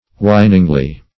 whiningly - definition of whiningly - synonyms, pronunciation, spelling from Free Dictionary Search Result for " whiningly" : The Collaborative International Dictionary of English v.0.48: Whiningly \Whin"ing*ly\, adv.